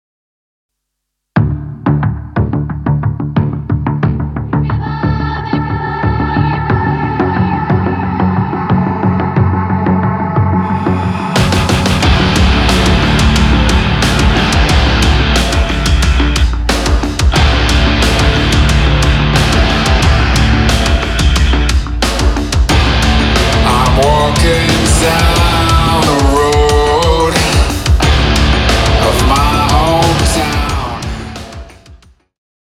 metal music rock